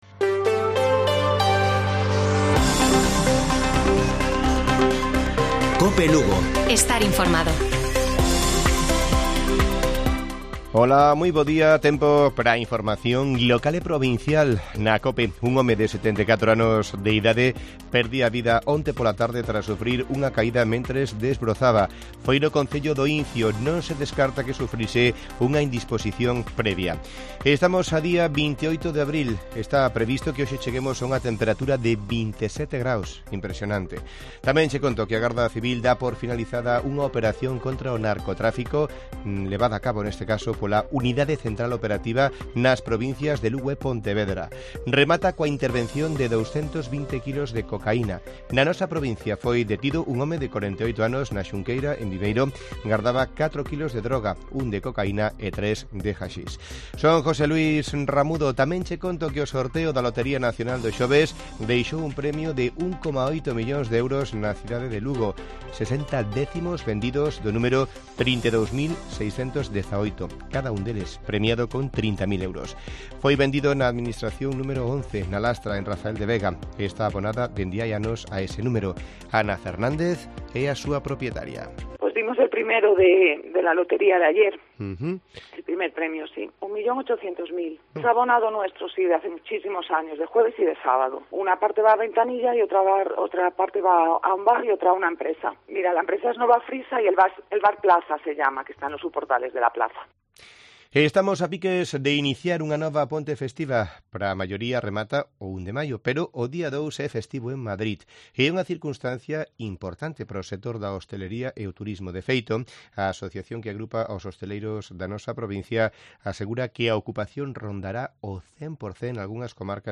Informativo Mediodía de Cope Lugo. 28 de abril. 13:20 horas